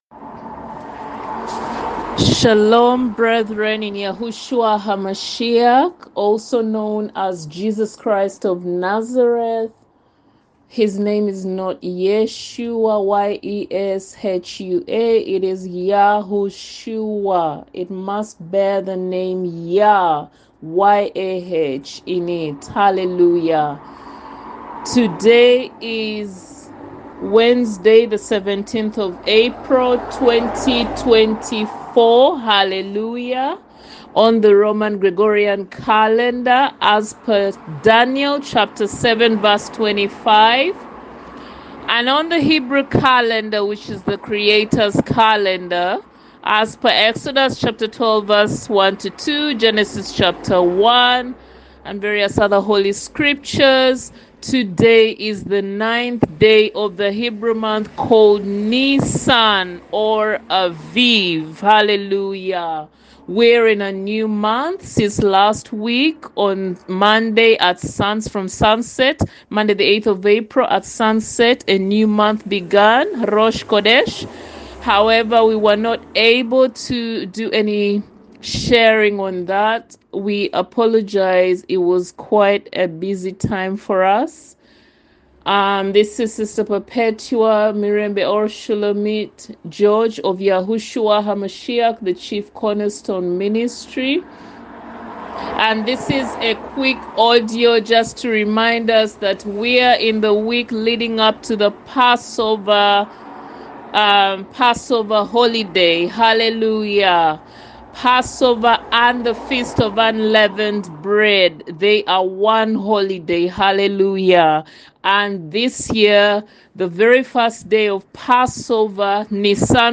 PESACH PASSOVER 2024 Short Audio Ministration